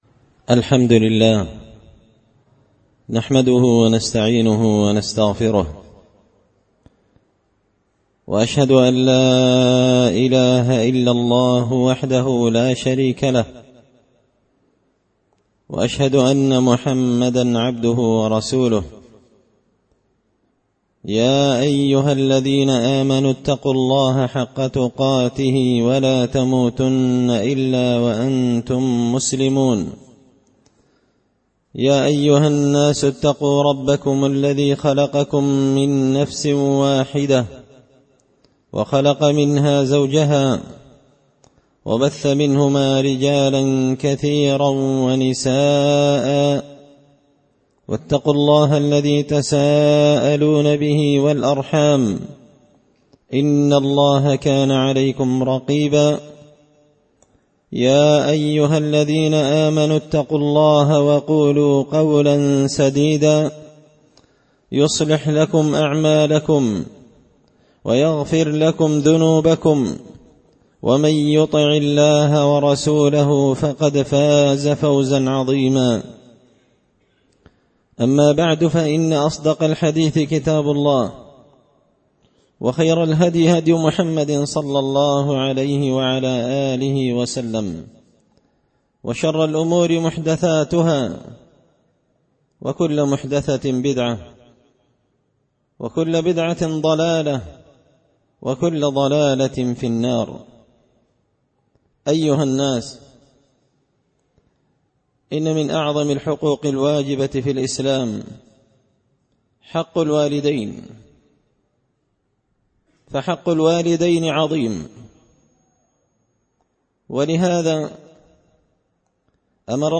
خطبة جمعة بعنوان – حق الوالدين الجزء الأول
دار الحديث بمسجد الفرقان ـ قشن ـ المهرة ـ اليمن